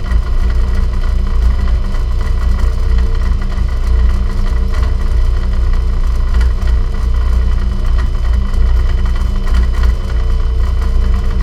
drill-loop.wav